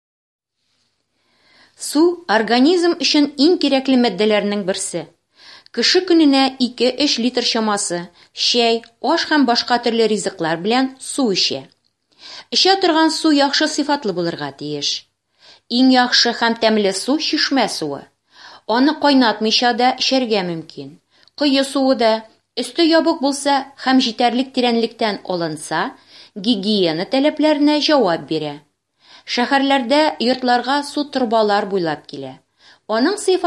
Диалоги 1-ой части (задания 1-8) Единого республиканского тестирования по татарскому языку в русскоязычных группах. Они направлены для тренировки понимания содержания услышанного и прочитанного текста.